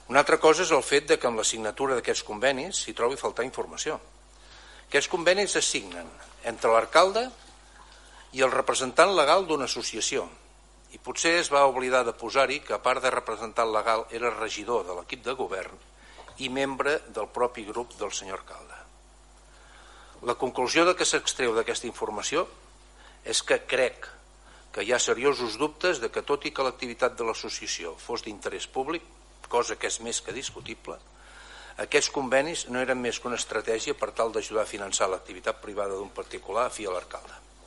Picabaralla al Ple de Palafrugell, entre Jaume Palahí i Juli Fernández